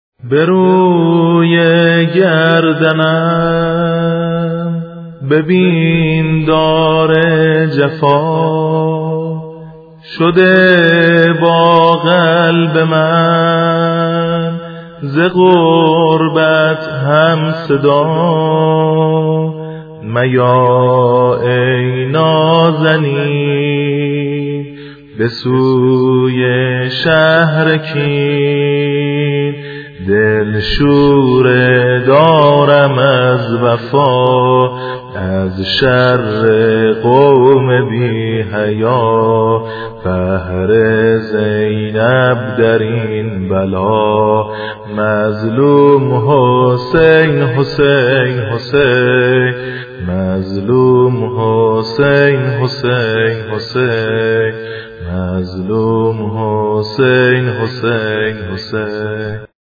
مسلم بن عقیل - - -- - -نوحه شهادت حضرت مسلم بن عقیل (ع)- - -